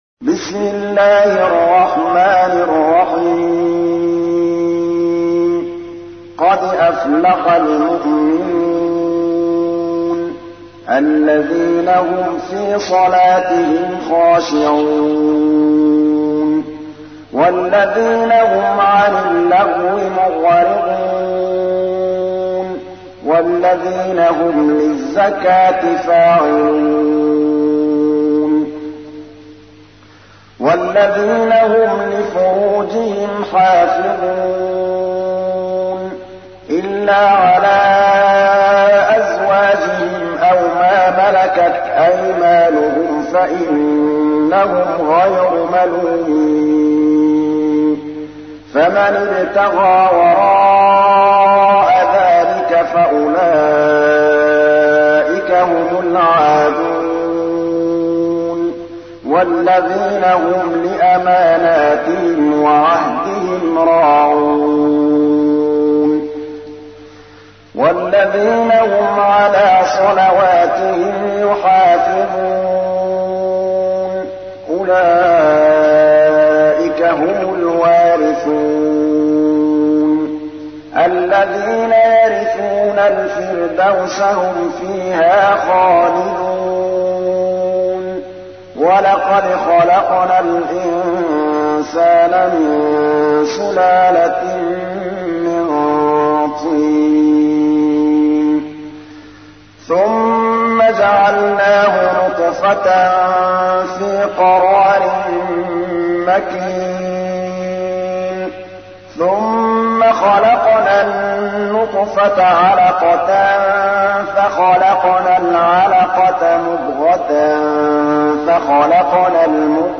تحميل : 23. سورة المؤمنون / القارئ محمود الطبلاوي / القرآن الكريم / موقع يا حسين